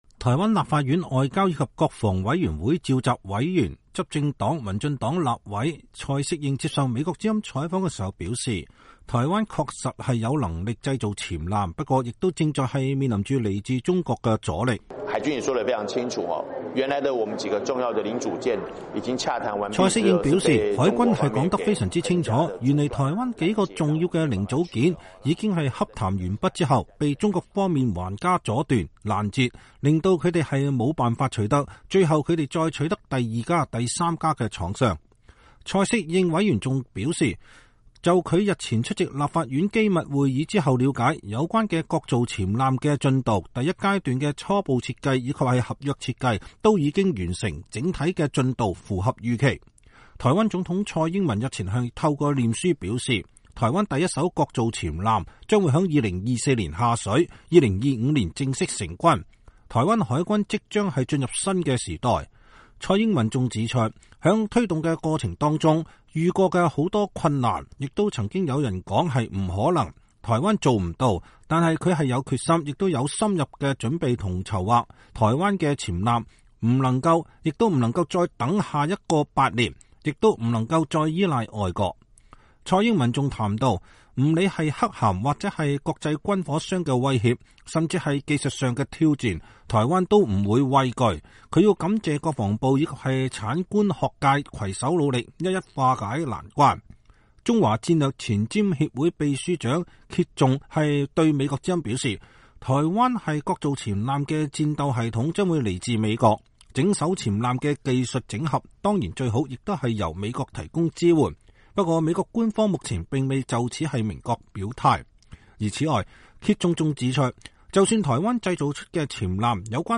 台灣立法院外交及國防委員會召集委員、執政黨民進黨立委蔡適應接受美國之音採訪表示，台灣確實有能力製造潛艦，不過也正面臨來自於中國的阻力。